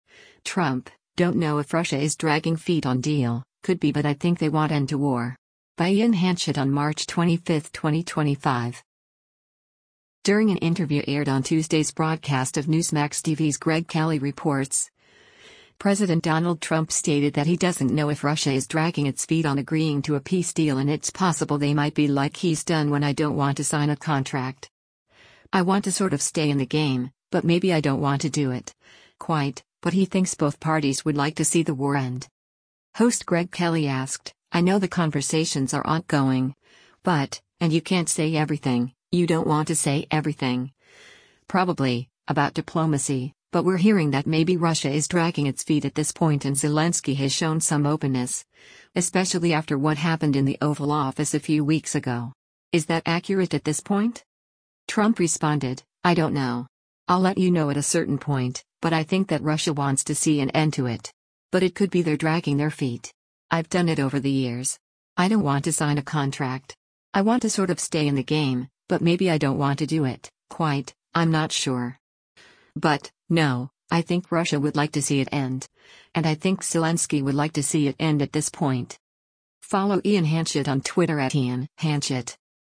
During an interview aired on Tuesday’s broadcast of Newsmax TV’s “Greg Kelly Reports,” President Donald Trump stated that he doesn’t know if Russia is dragging its feet on agreeing to a peace deal and it’s possible they might be like he’s done when “I don’t want to sign a contract. I want to sort of stay in the game, but maybe I don’t want to do it, quite,” but he thinks both parties would like to see the war end.